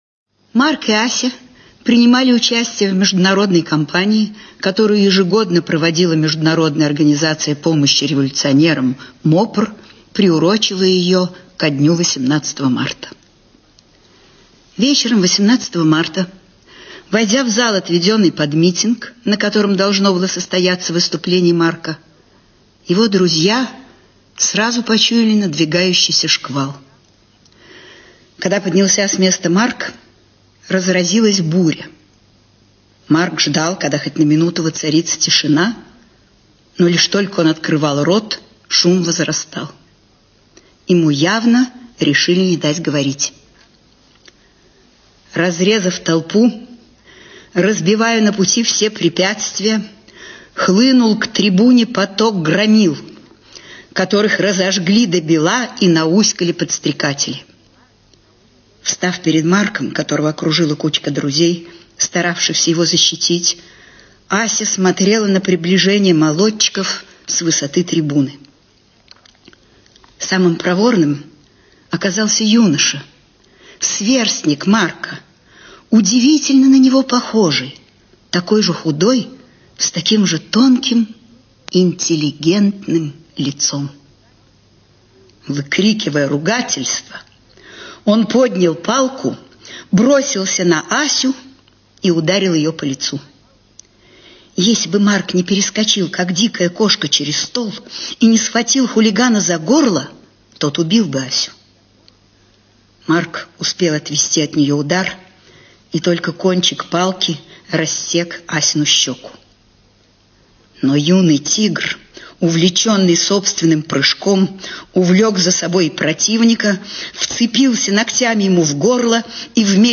ЧитаетЮнгер Е.